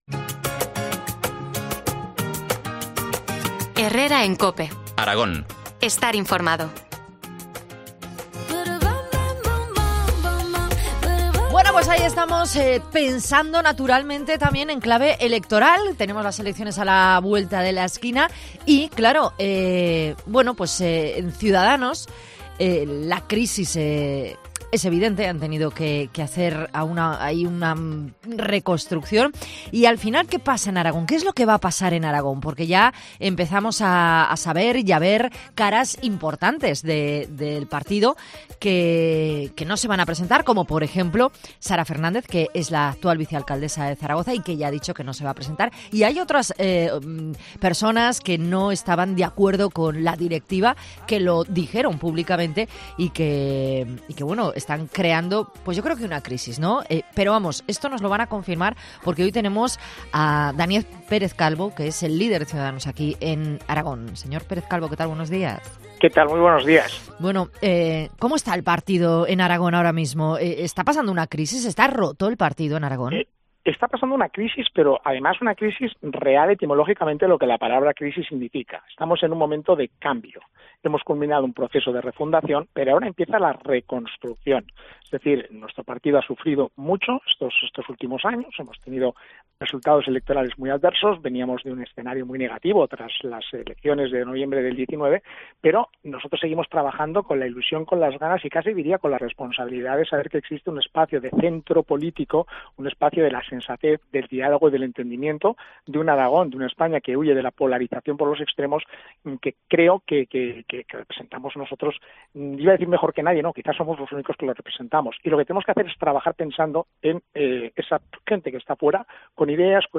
Entrevista a Daniel Pérez Calvo, líder de C's en Aragón